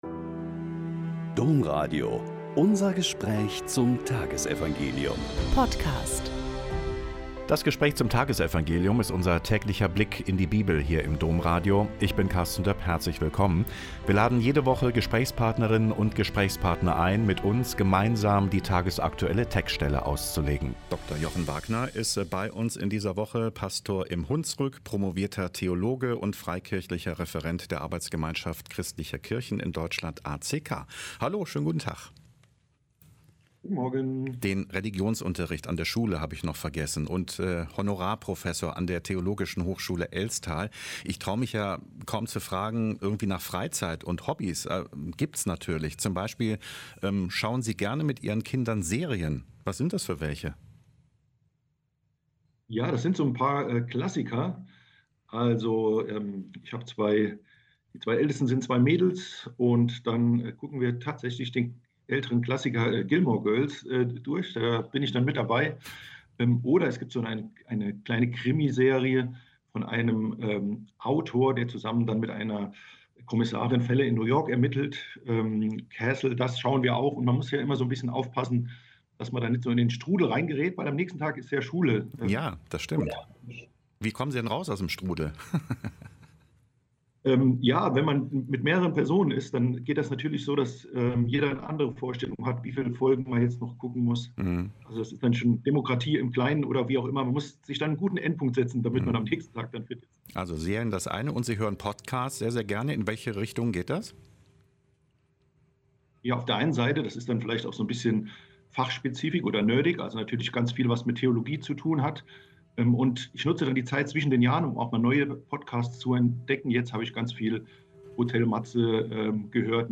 Mk 3,13-19 - Gespräch